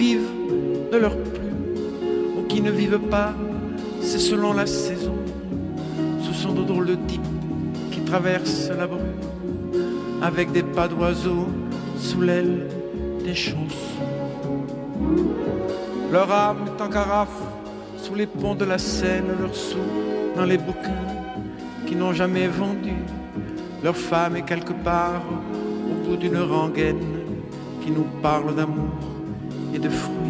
"enPreferredTerm" => "Chanson francophone"